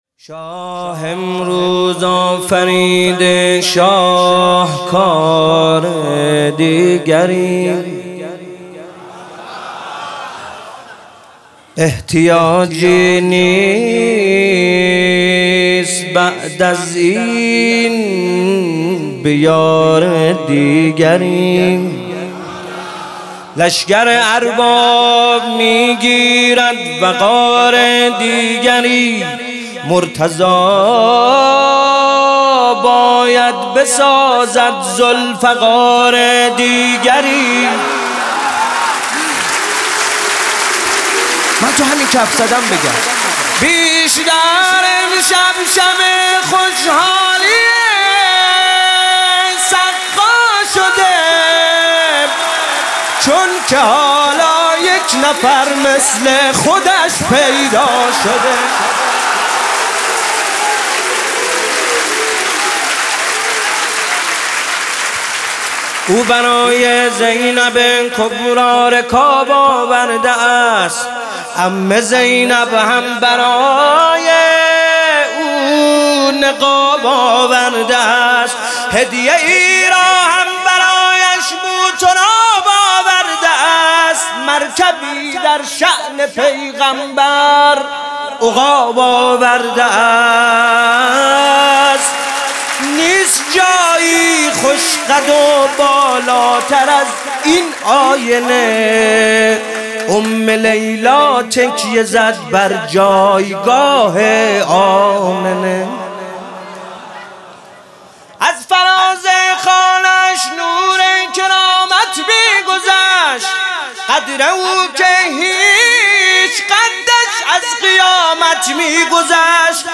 مدح: شاه امروز آفریده شاهکار دیگری